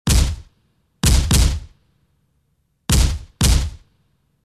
Efectos de sonido
GOLPES EFECTOS ESPECIALES
Golpes_efectos_especiales.mp3